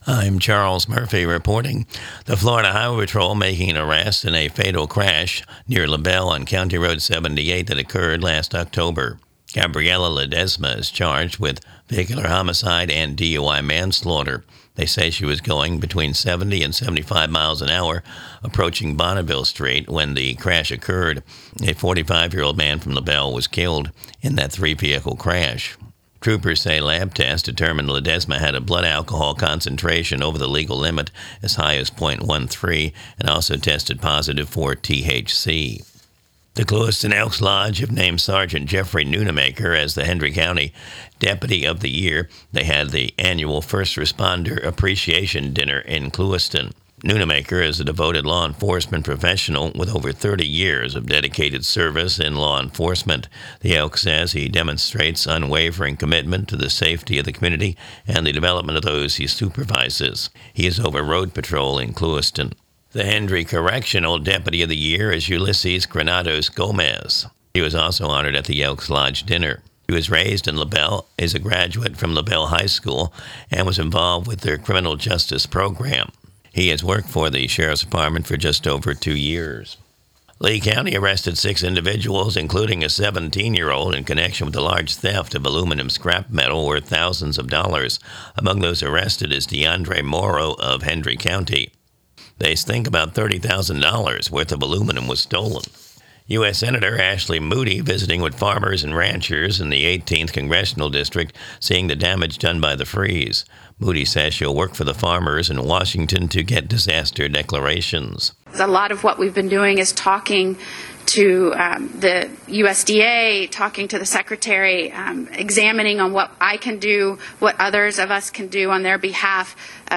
NEWS
Recorded from the WAFC daily newscast (Glades Media).